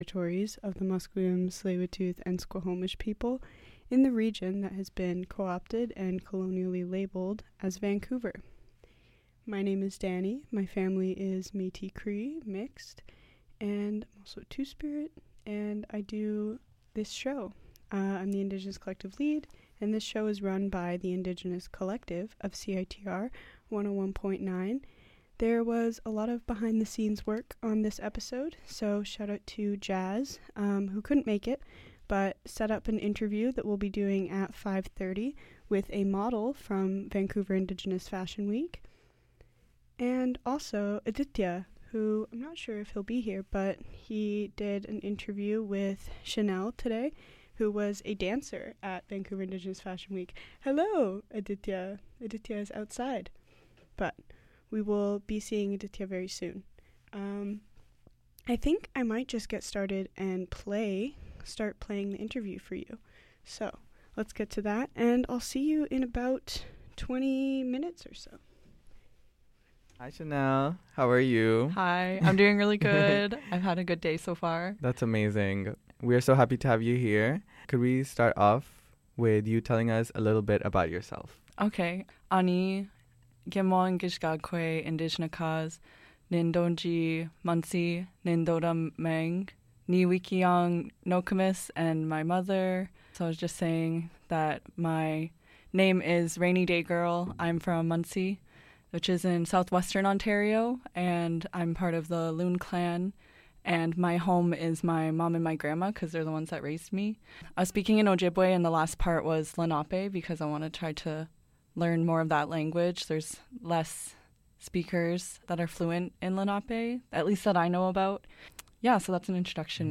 Today, we interview